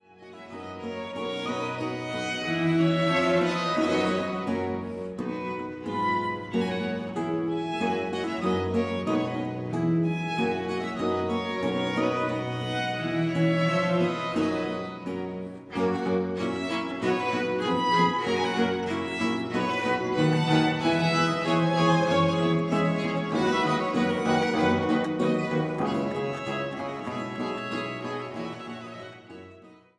Violine